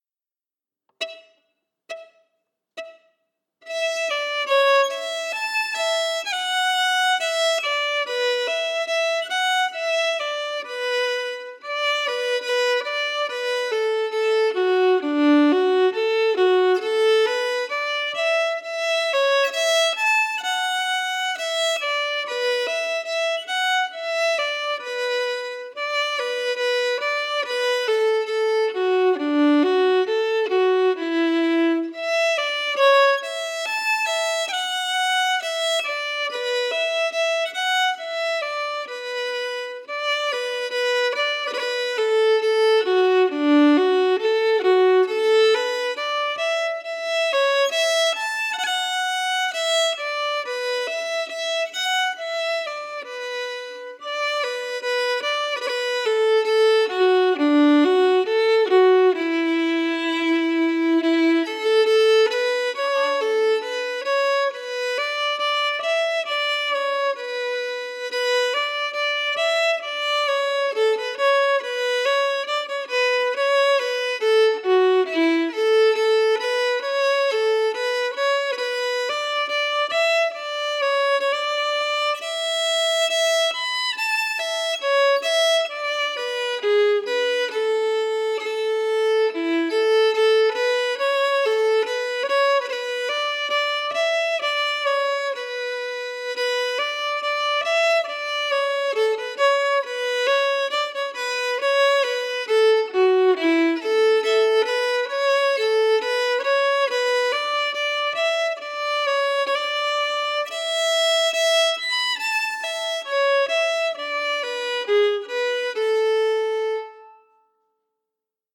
Key: A
Form: Slow reel
Slow for learning